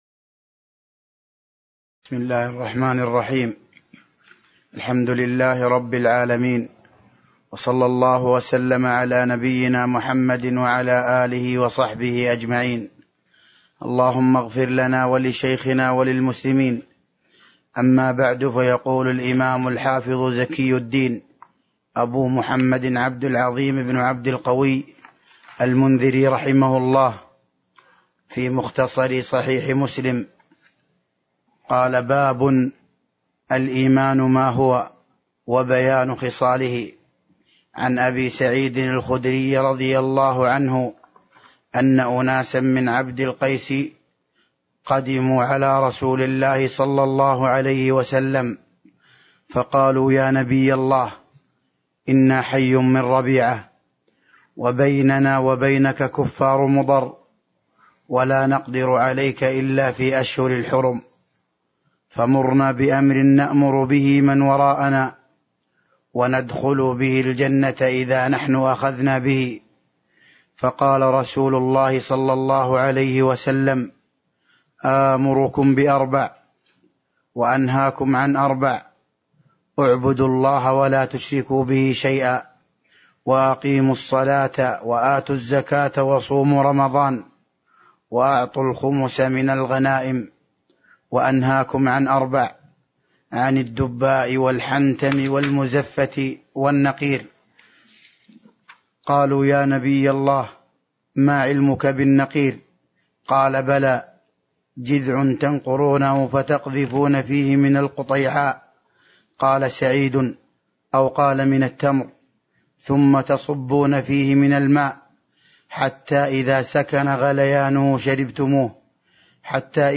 تاريخ النشر ١٧ صفر ١٤٤٢ هـ المكان: المسجد النبوي الشيخ